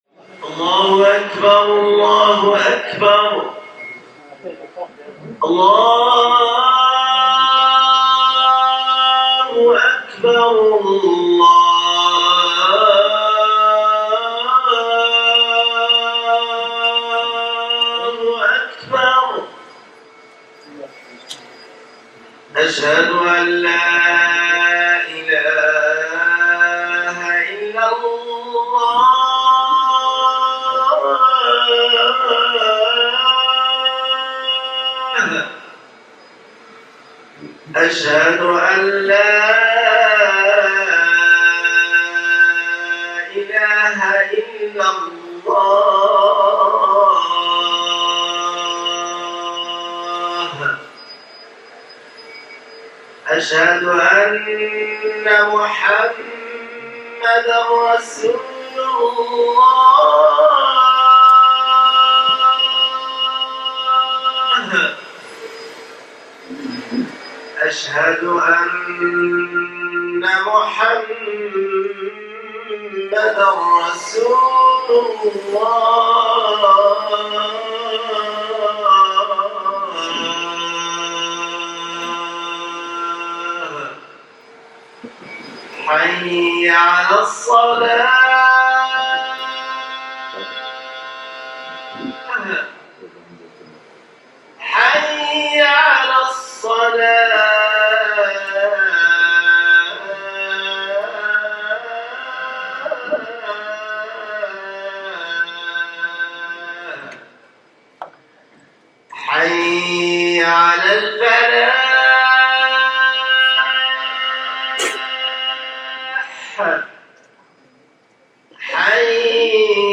Muslim Community Center - MCC East Bay /Adhan (Islamic Call to Prayer) in American Sign Language (ASL)